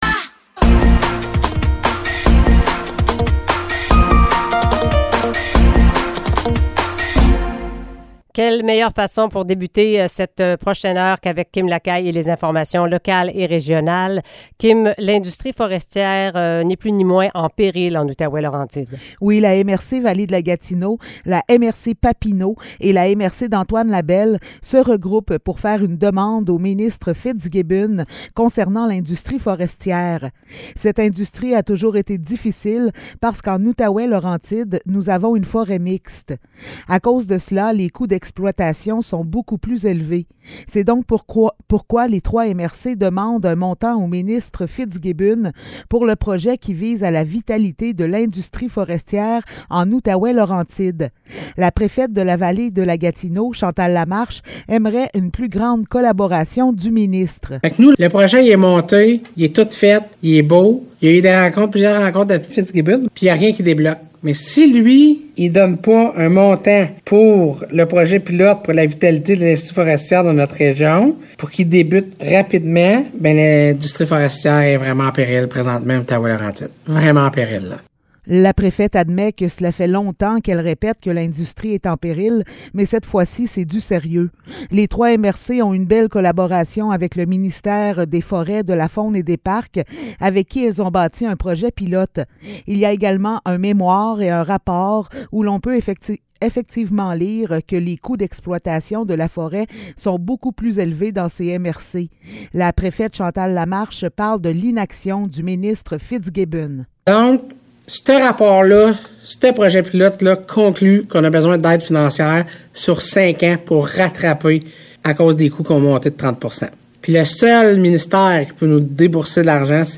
Nouvelles locales - 20 janvier 2023 - 7 h